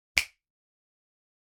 Finger Snap Sound - Bouton d'effet sonore